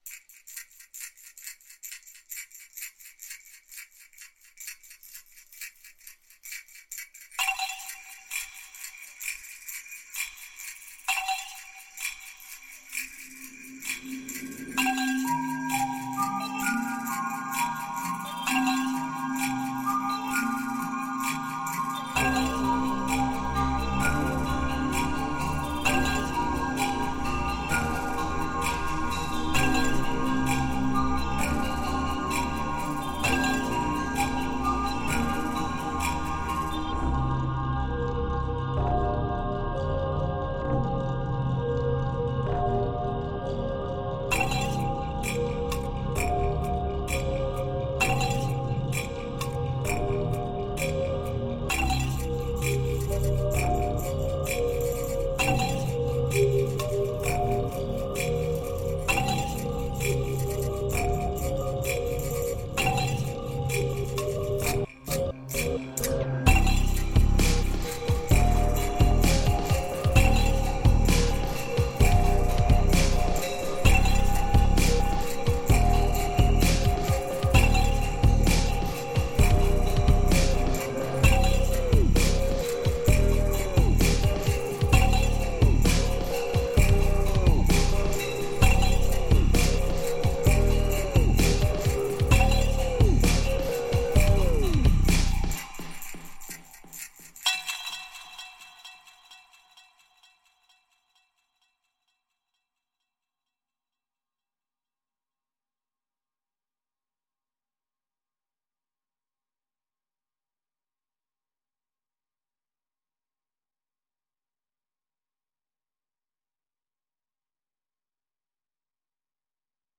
Self-produced soundscape using 1 object